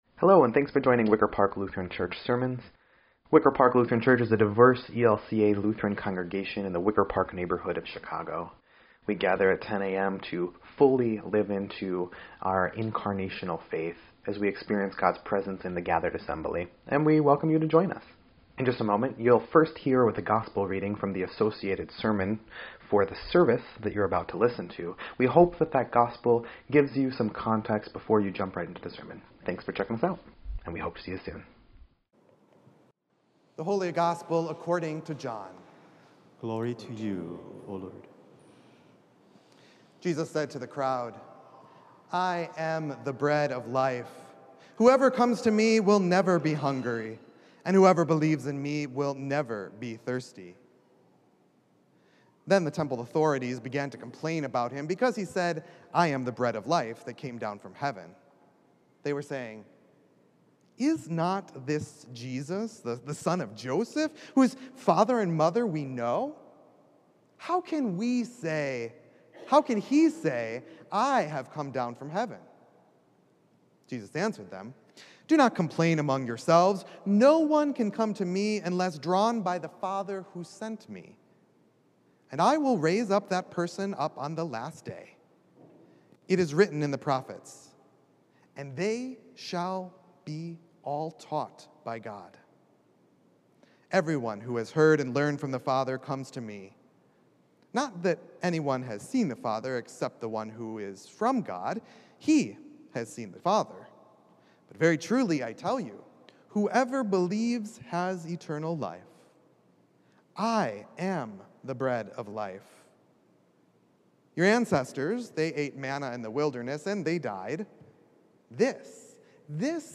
8.8.21-Sermon_EDIT.mp3